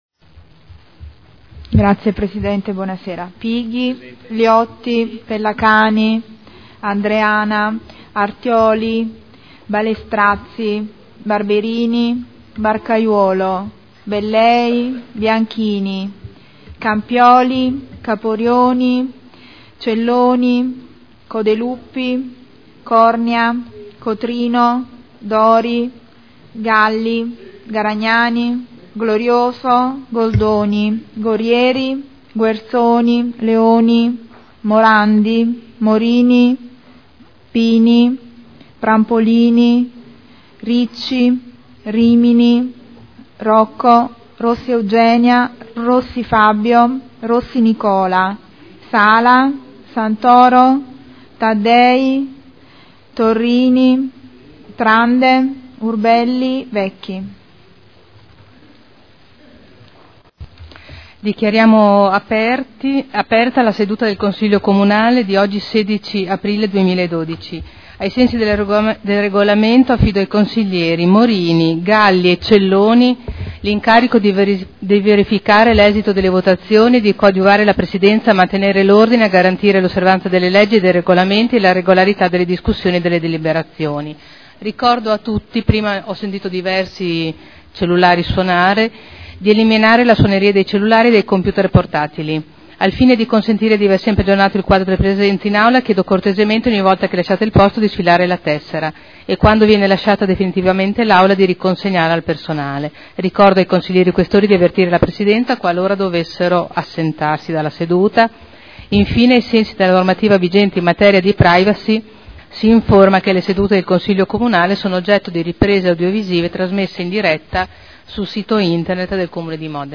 Segretario Generale — Sito Audio Consiglio Comunale
Seduta del 16 aprile. Apertura del Consiglio Comunale Appello